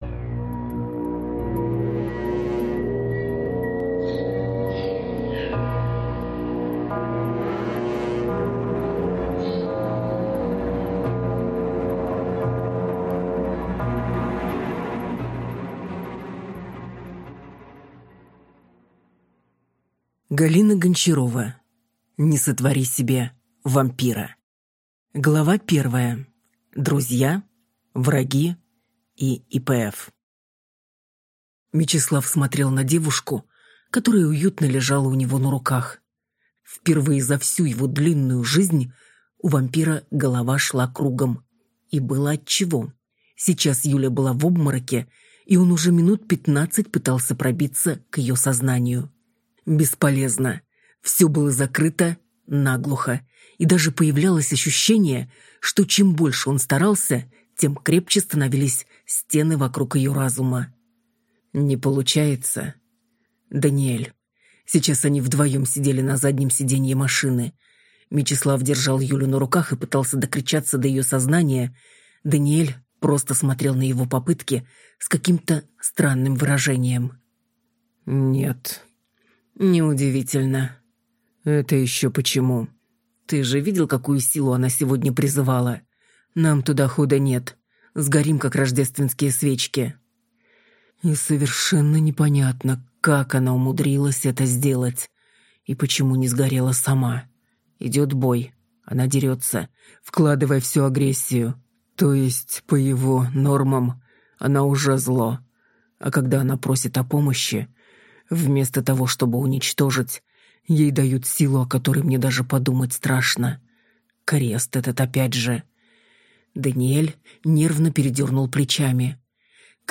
Аудиокнига Не сотвори себе вампира | Библиотека аудиокниг